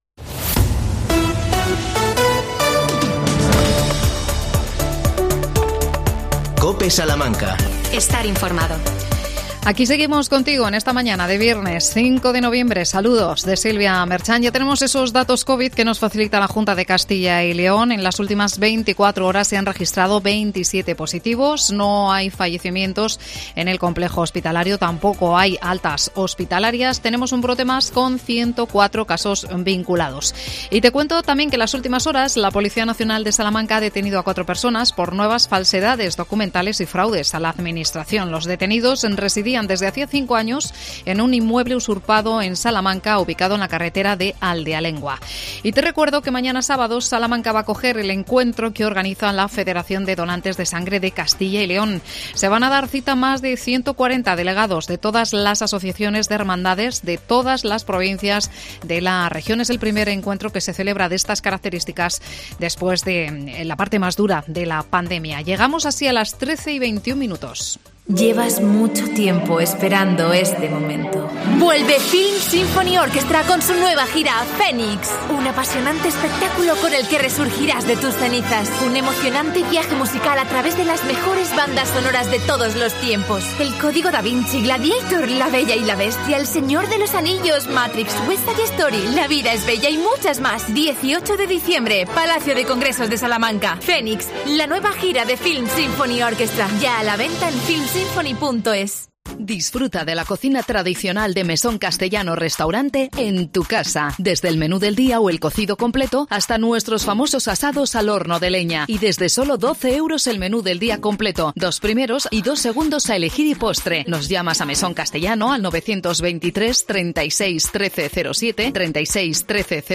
AUDIO: Entrevista a Jesús Sánchez. Es el primer Defensor del Mayor en Salamanca.